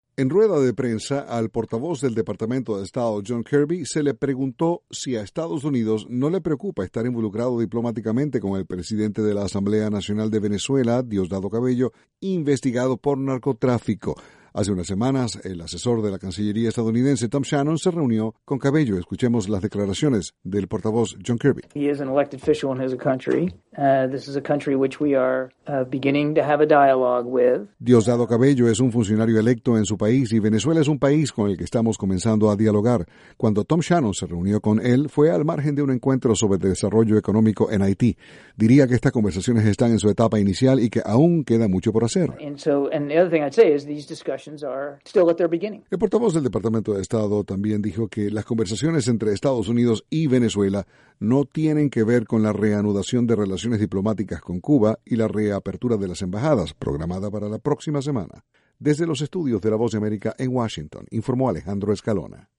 Estados Unidos continuara dialogando con Venezuela a pesar de senalamientos segun los cuales un alto funcionario de ese pais estaria investigado por narcotrafico. Desde la Voz de America, Washington, informa